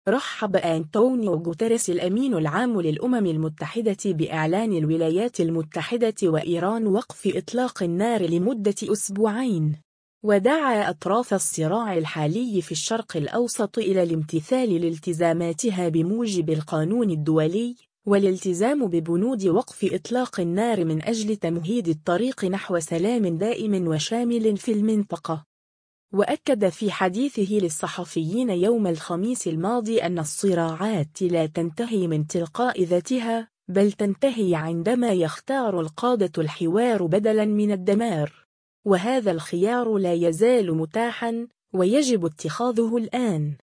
وأكد في حديثه للصحفيين يوم الخميس الماضي أن الصراعات لا تنتهي من تلقاء ذاتها، بل “تنتهي عندما يختار القادة الحوار بدلا من الدمار. وهذا الخيار لا يزال متاحا، ويجب اتخاذه الآن”.